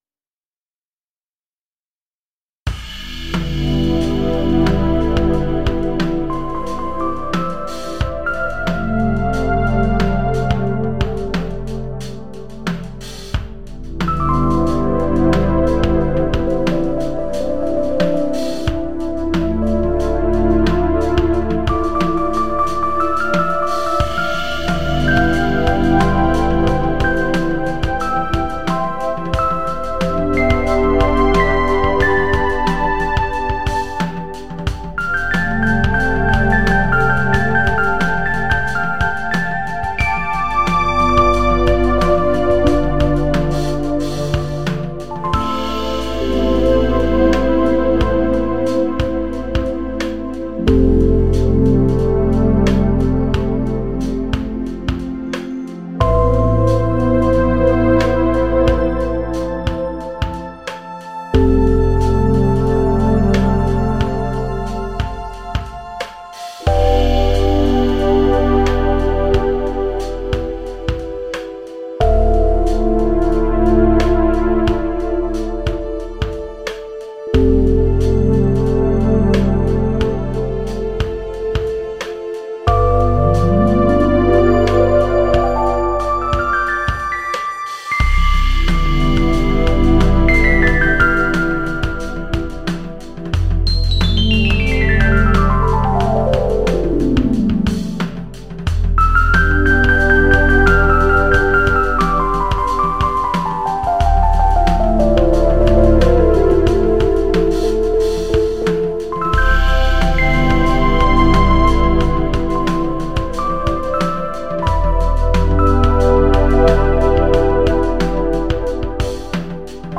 3. 氛围铺底
这个音色库可以让你体验到柔和、氛围、低保真和电影风格的声音。
用铃铛、锣、吉他（吉布森、斯特兰德伯格、芬德）、旧钢琴和硬件合成器（Matrixbrute、Deepmind 12）的录音创建的声源，然后进行调整和定制，赋予它们独特的个性和风味。
- Motion -> arpeggiated sounds and moving sounds via LFO modulation